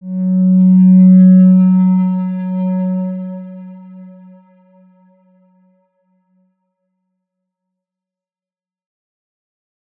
X_Windwistle-F#2-mf.wav